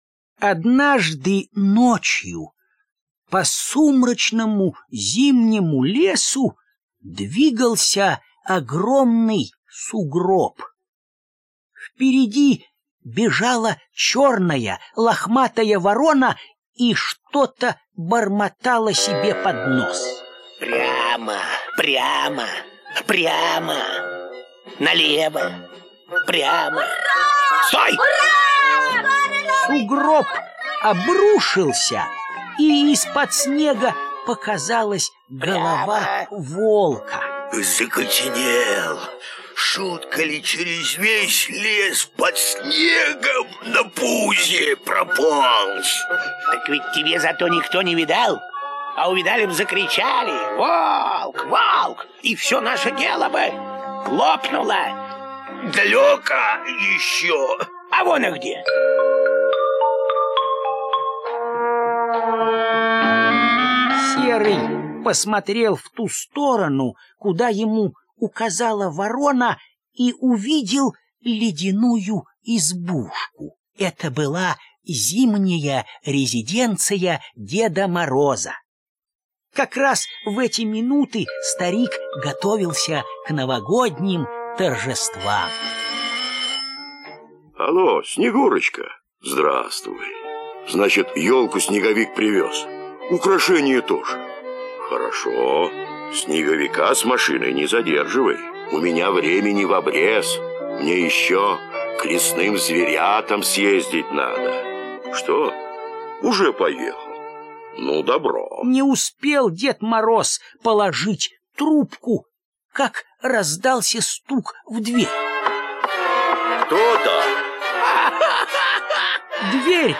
Аудиосказка Дед Мороз и серый волк - Детские рассказы